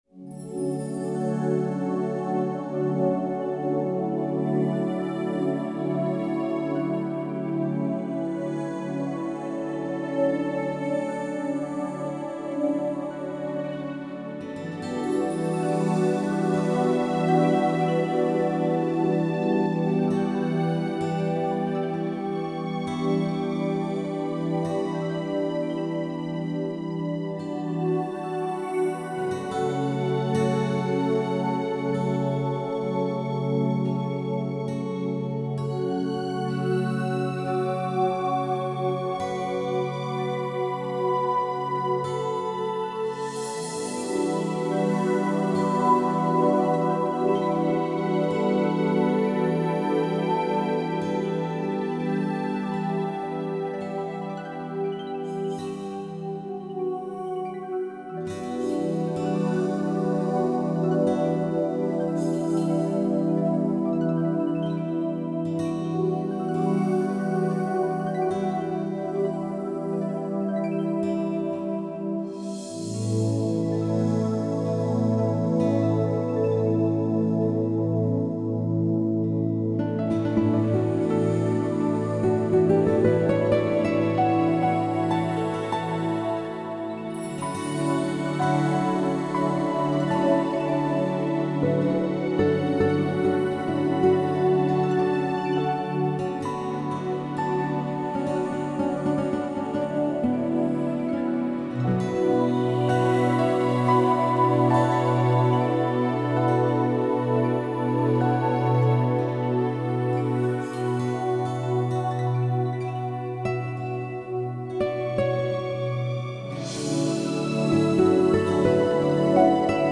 Znova hudba vhodná pre reiki, relaxáciu a meditáciu.